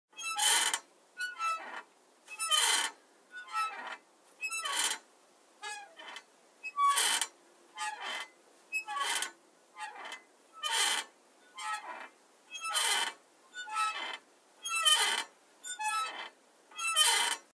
Поскрип качающейся качели